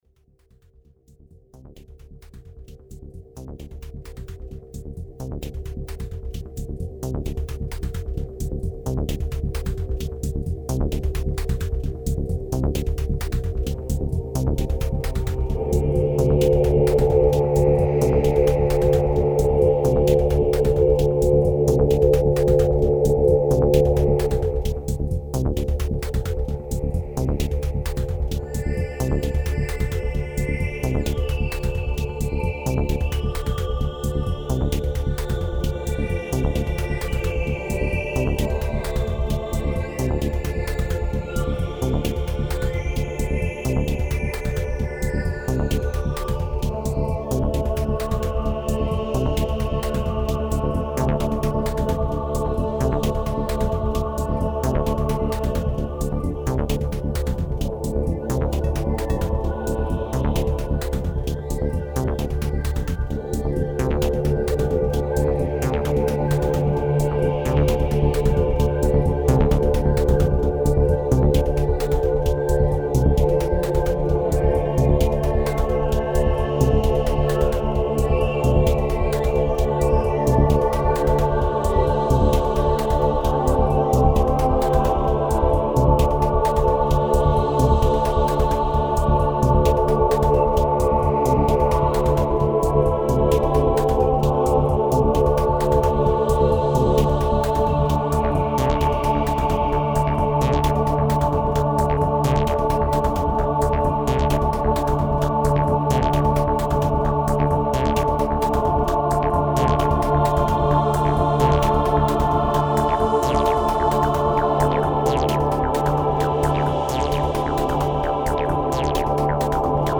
Tempo: 60 bpm / Datum: 29.09.2016